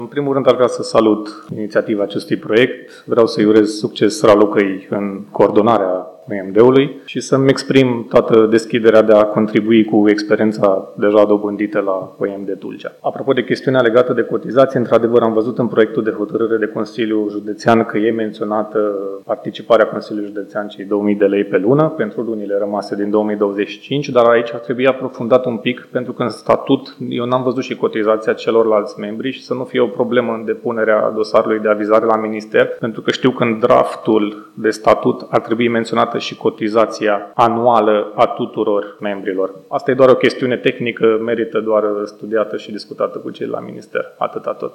Vineri, 27 iunie, Consiliul Județean Tulcea a votat înființarea Organizației de Management al Destinației Turistice „Eco‑Delta”.
La rândul său, consilierul PNL Alexandru Filip, coordonatorul OMD Tulcea (organizație distinctă, înființată anterior cu sprijinul Primăriei), a salutat inițiativa: